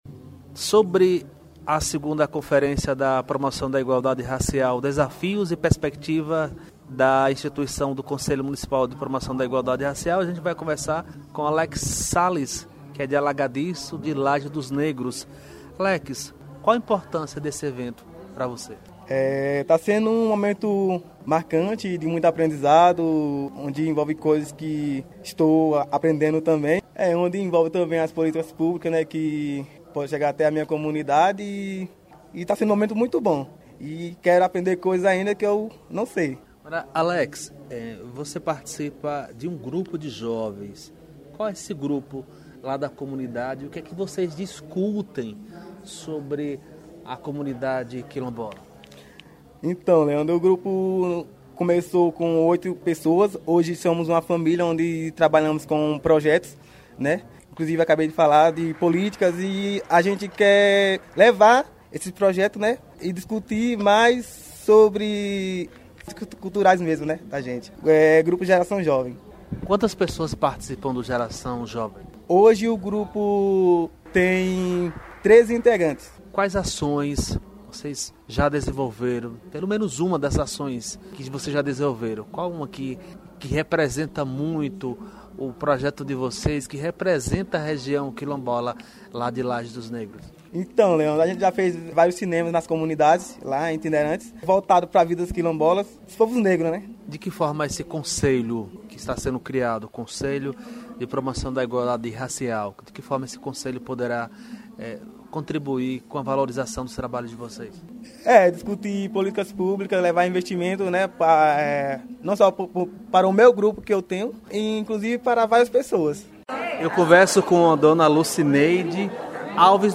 Representantes das comunidades quilombolas de CFormoso na 2ª Conferência municipal da promoção de Igualdade Racial